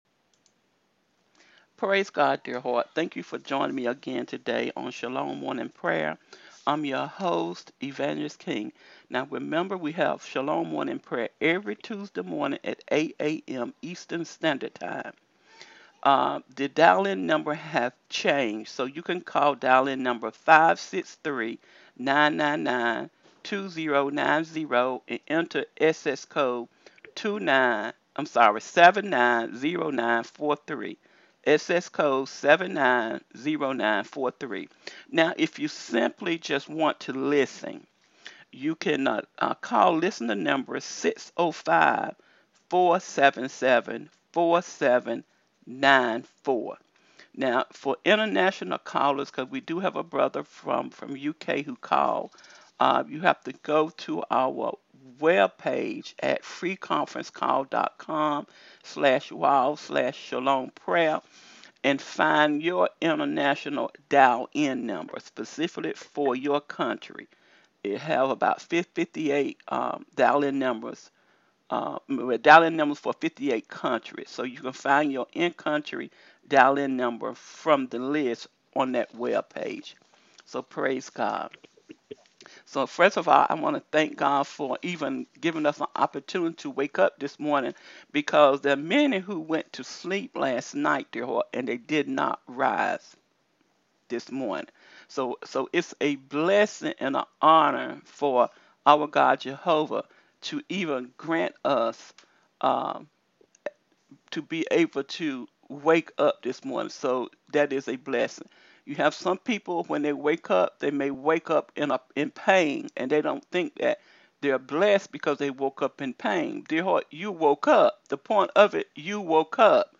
ALL CALLS ARE PUBLIC, RECORDED AND SHARED ON ALL OUR PROGRAMS.